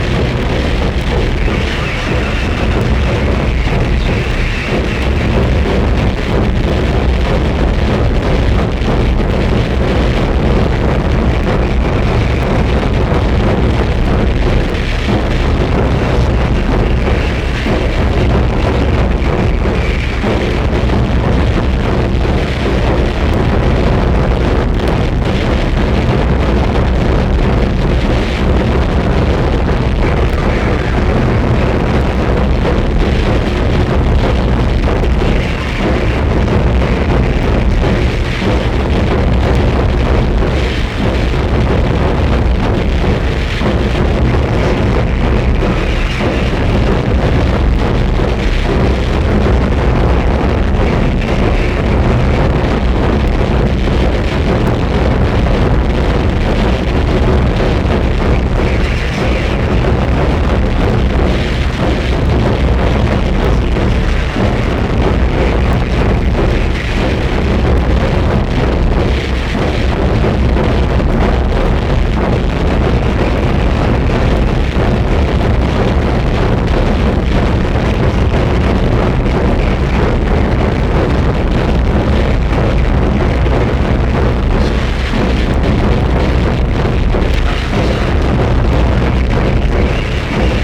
WORLD / OTHER / NEW WAVE / AFRICA / BALEARIC